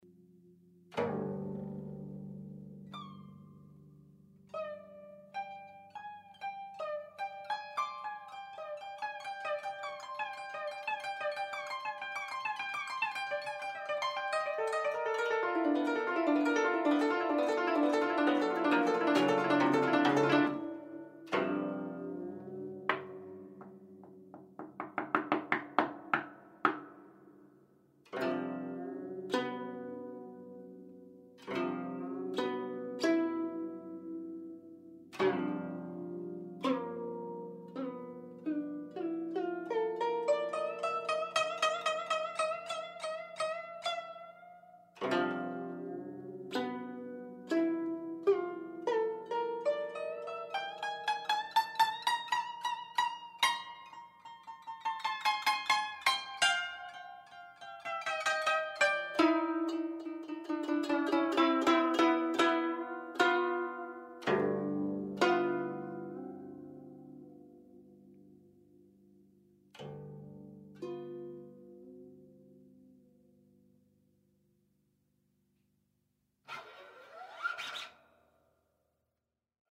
guzheng